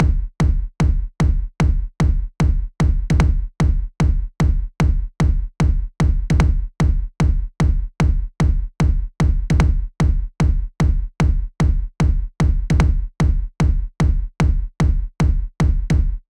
特にバスドラムのアタック音とサブウーファー帯域の繋がりがよろしくなって自然な感じに。
※低音のよく出るヘッドホンか大きいスピーカーでないと分かりにくいです。
Kick.m4a